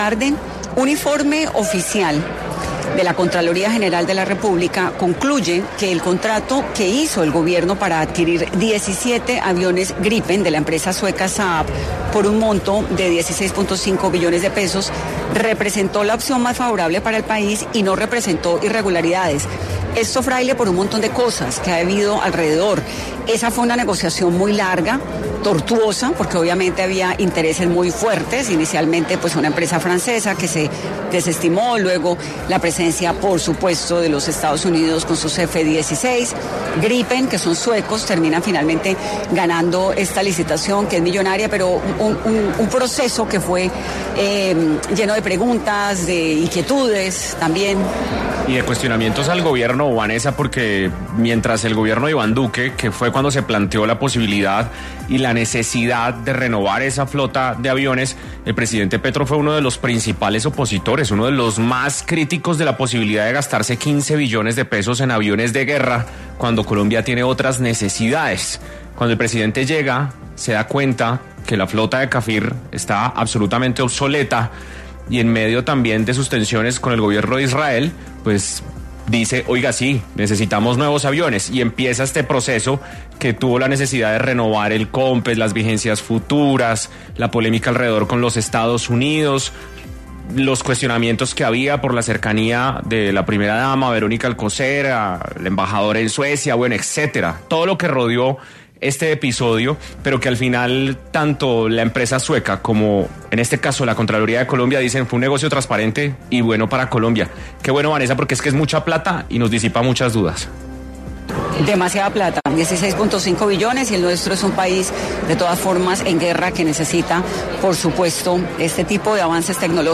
En diálogo con Vanessa de la Torre para el programa Dos Puntos de Caracol Radio, el ministro de Defensa, Pedro Sánchez, se pronunció a propósito del informe de la Contraloría General de la República que dio luz verde a la compra de aviones Gripen.